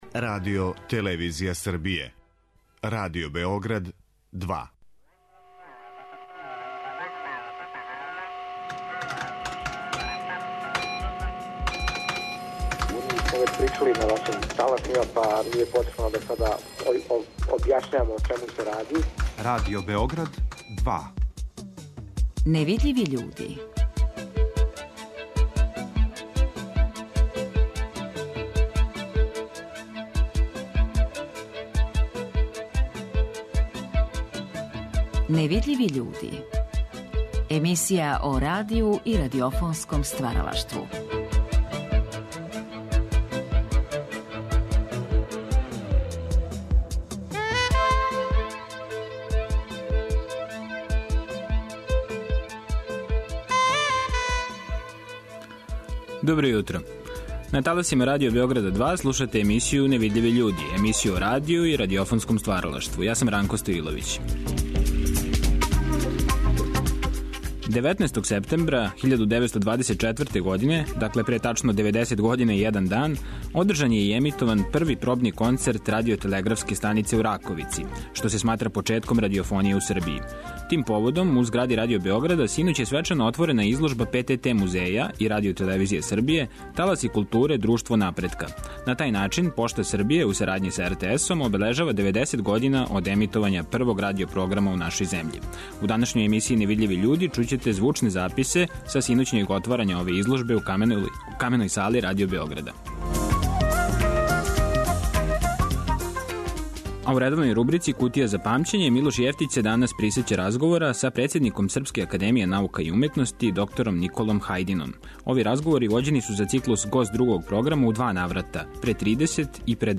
Емисија о радију и радиофонском стваралаштву.